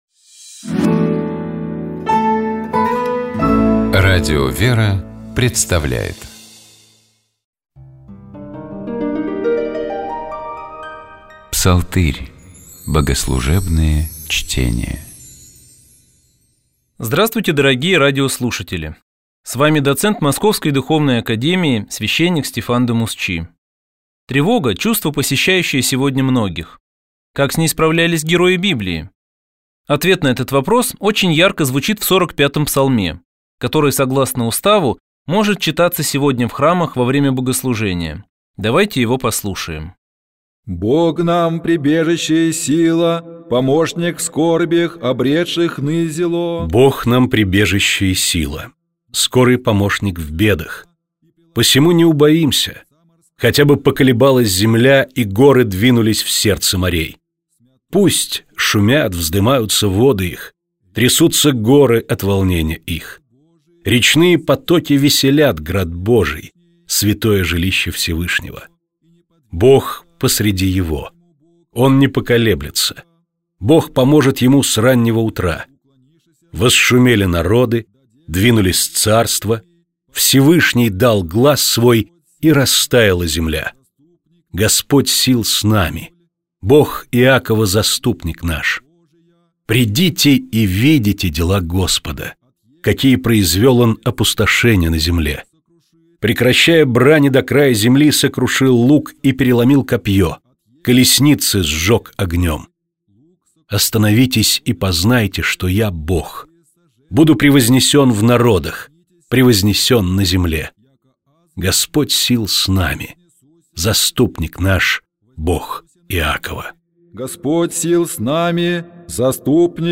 Псалтирь: богослужебные чтения